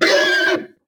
ogg / general / combat / creatures / horse / he / hurt1.ogg
hurt1.ogg